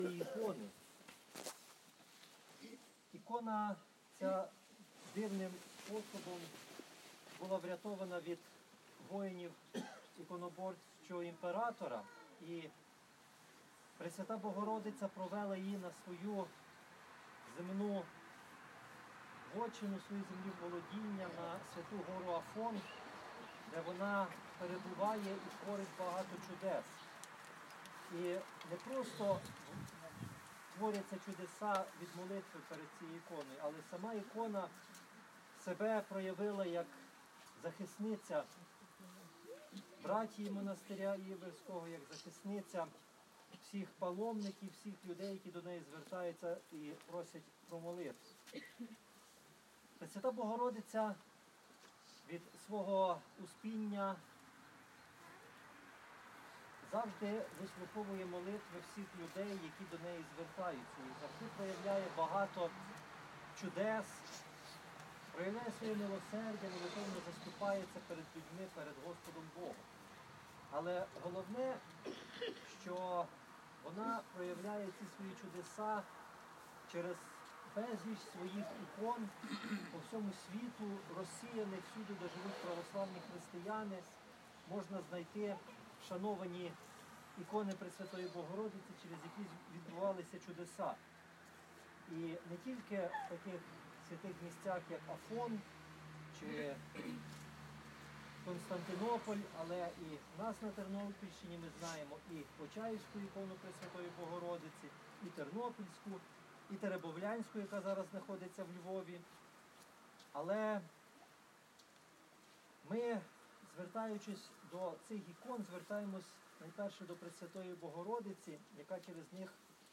У своєму повчальному слові владика Нестор побажав, щоб Пресвята Богородиця й надалі захищала громаду і весь православний світ.